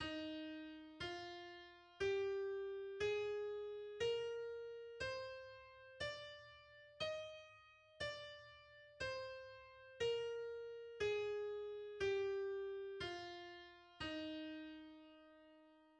E-flat major - Wikipedia
E-flat major is a major scale based on E, consisting of the pitches E, F, G, A, B, C, and D. Its key signature has three flats.
The E-flat major scale is: